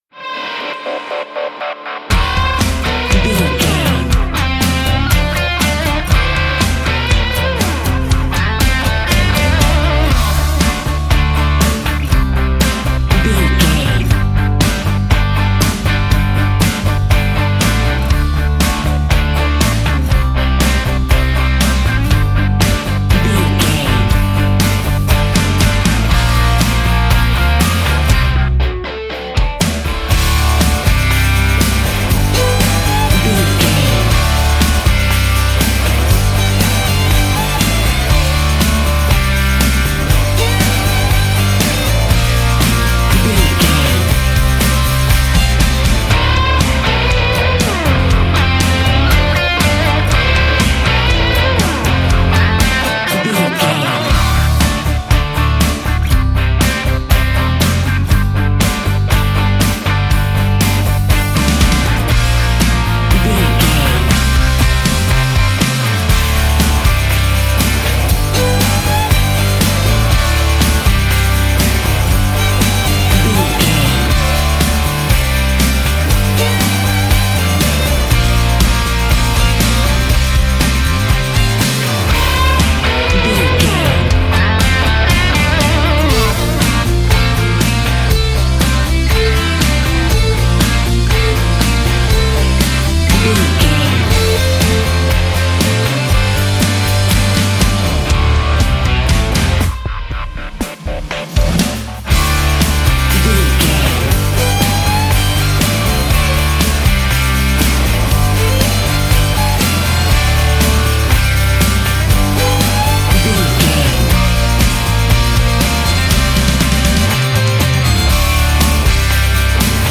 Aeolian/Minor
drums
electric guitar
bass guitar
violin